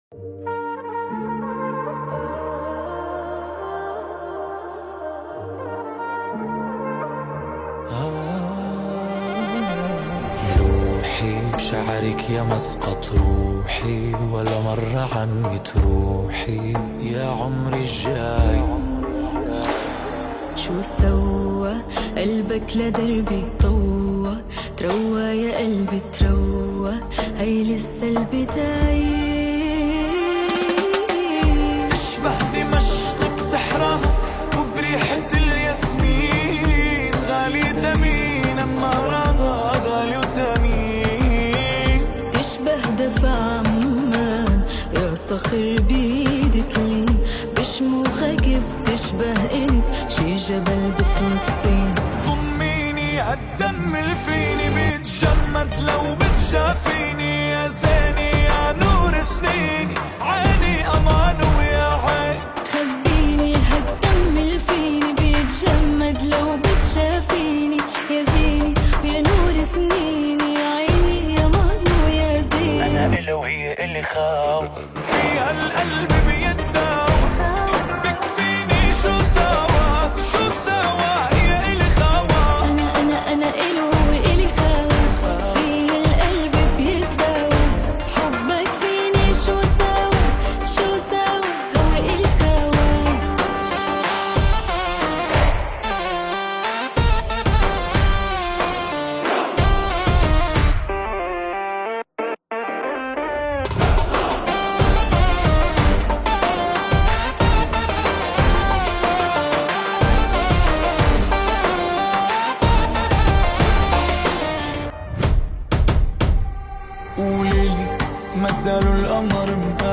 • اللهجة: بيضاء (مزيج شامي).